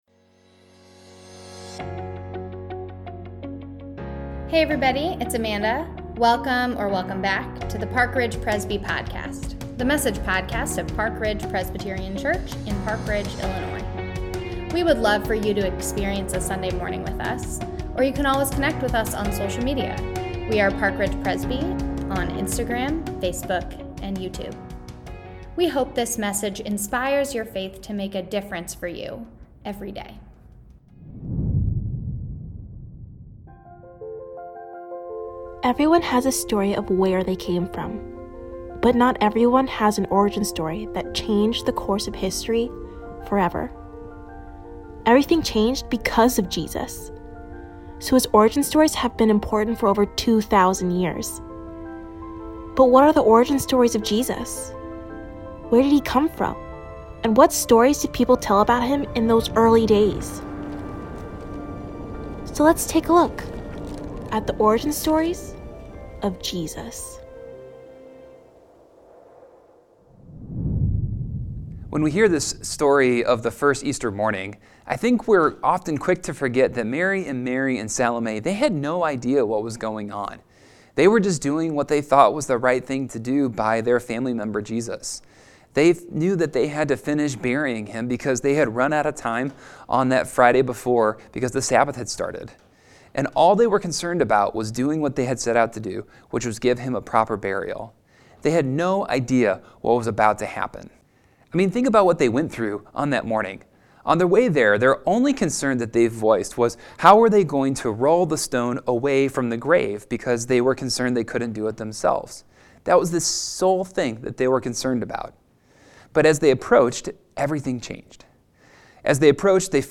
Easter Sunday | The Origin Stories of Jesus – Part 3 | Online Worship | April 17, 2022